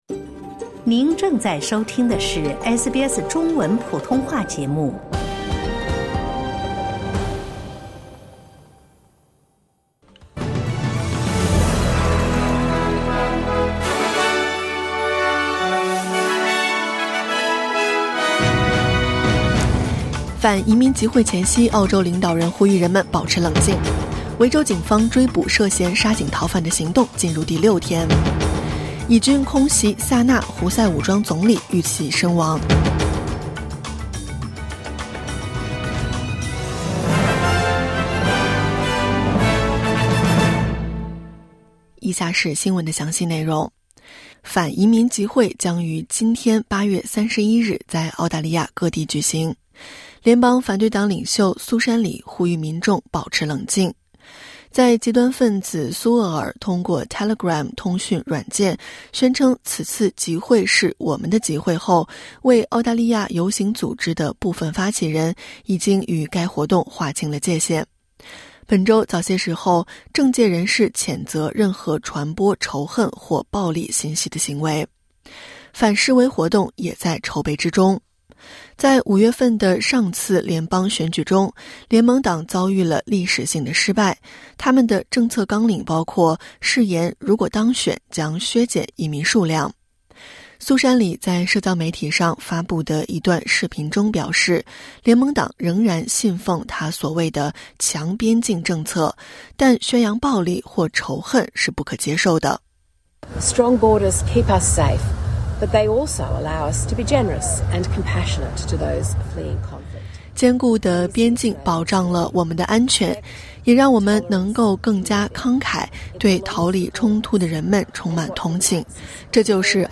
SBS早新闻（2025年8月31日）